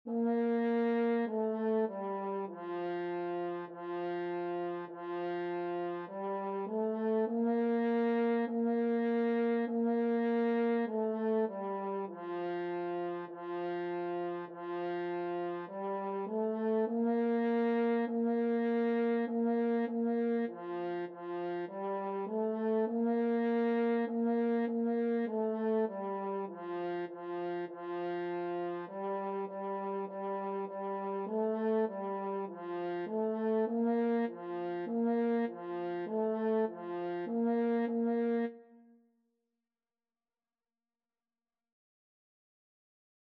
4/4 (View more 4/4 Music)
F4-Bb4
Beginners Level: Recommended for Beginners
French Horn  (View more Beginners French Horn Music)
Classical (View more Classical French Horn Music)